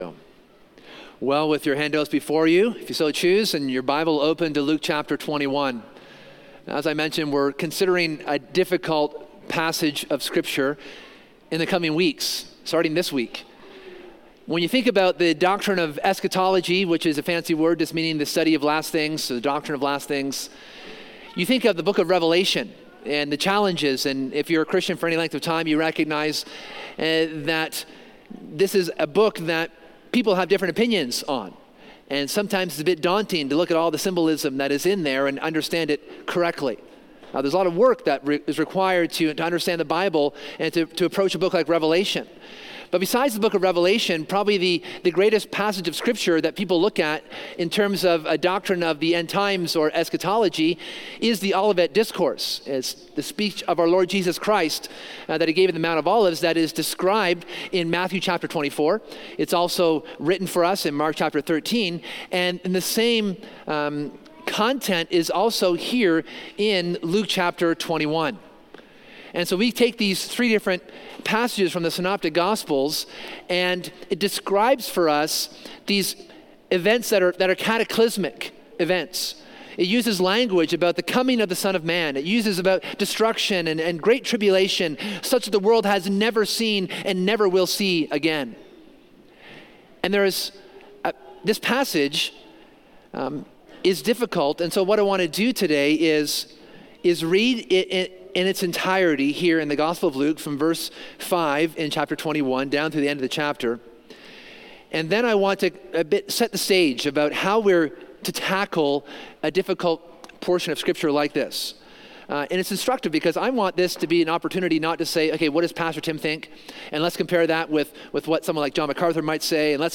This sermon begins an examination of the “Olivet Discourse” in the gospel of Luke where Jesus speaks about the destruction of Jerusalem and the temple.